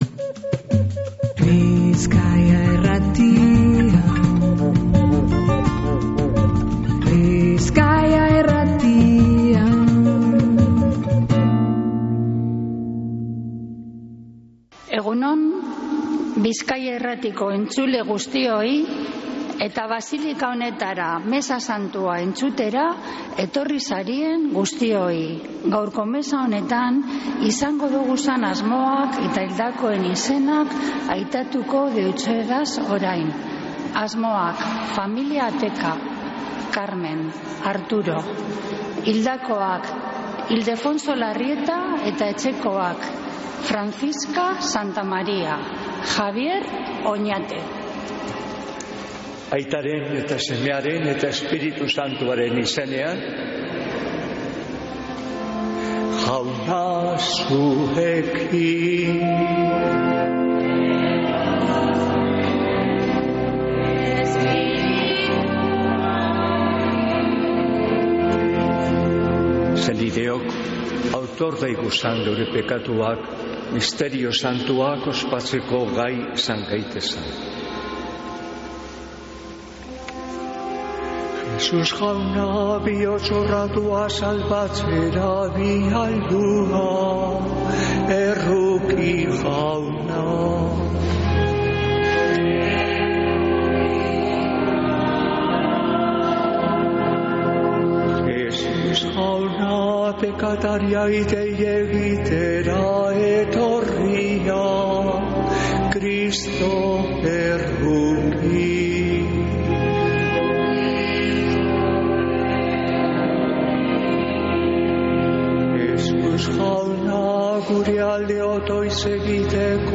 Mezea Begoñako basilikatik